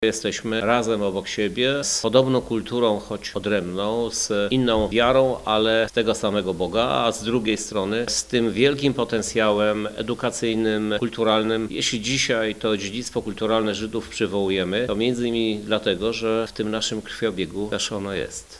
Krzysztof Żuk – mówi Krzysztof Żuk, Prezydent Lublina